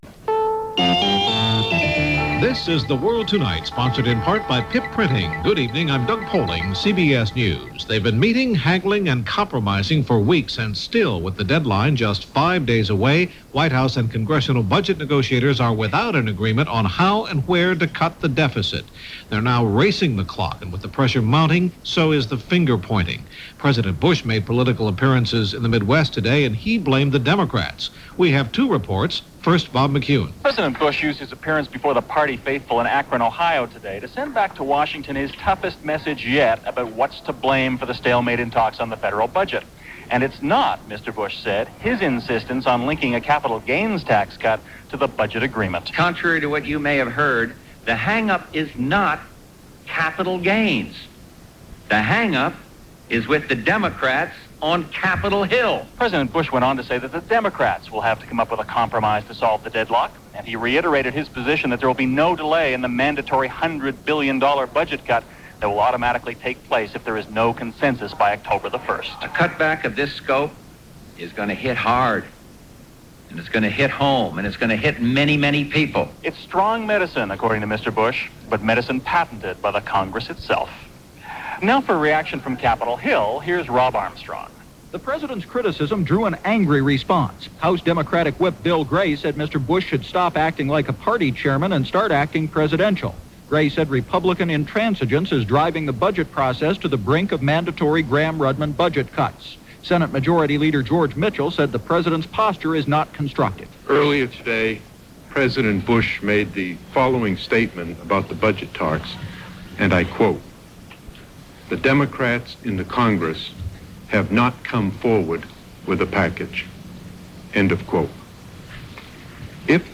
And that’s a very small slice of what went on this September 26, 1990 as reported by CBS Radio’s The World Tonight.